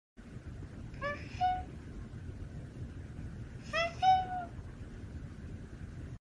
The sound of the cat sound effects free download
The sound of the cat coming